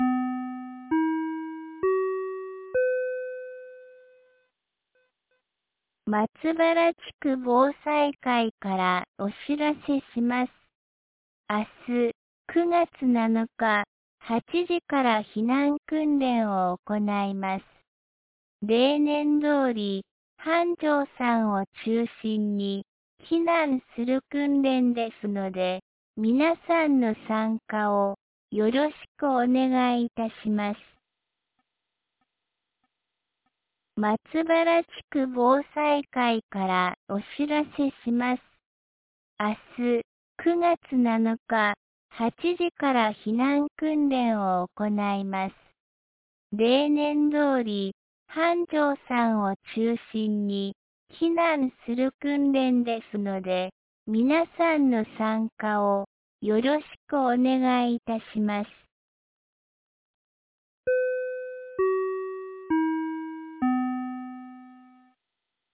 2025年09月06日 17時14分に、安芸市より井ノ口へ放送がありました。